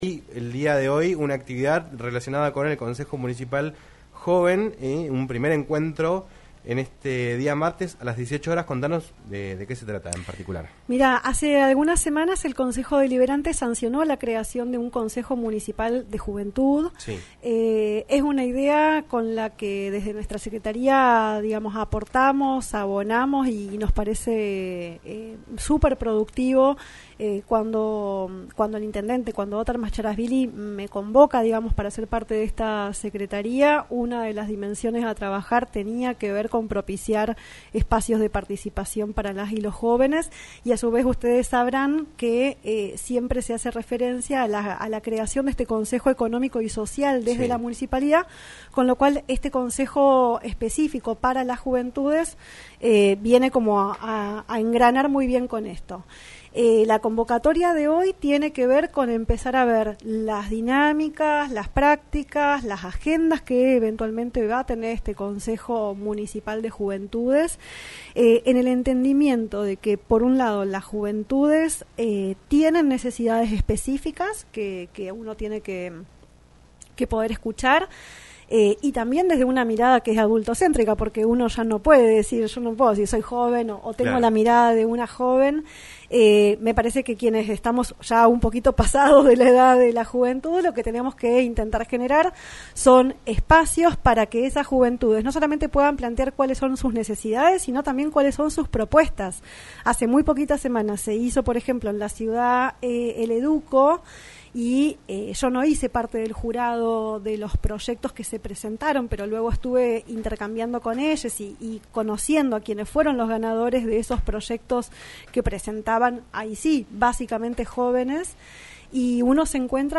Renata Hiller, titular de la Secretaría de la Mujer, Género, Juventud y Diversidad, pasó por los estudios de LaCienPuntoUno para hablar en “Un Millón de Guanacos” sobre el Ciclo de Charlas Enmarcado en el Mes de las Juventudes. Además, se refirió a cómo viven los más jóvenes el momento social que atraviesa el país y su participación en la política.